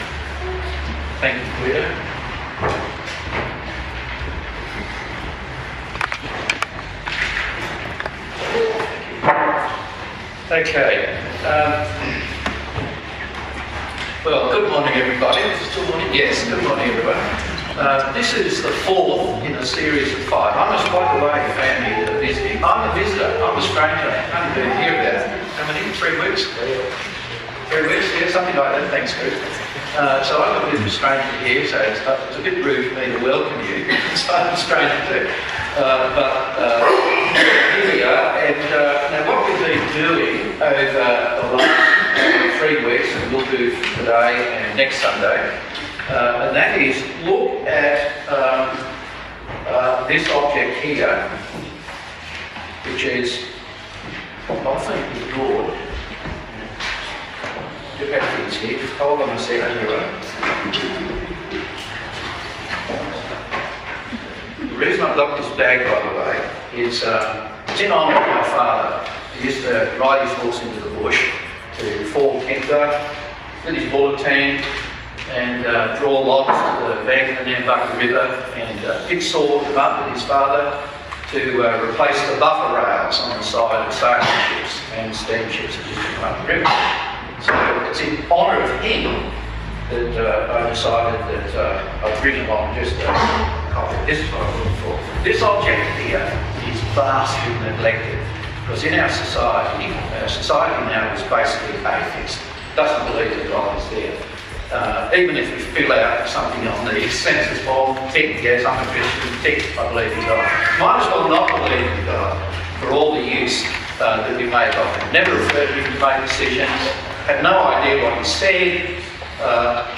The fourth in a series of five sermons.
Service Type: AM Service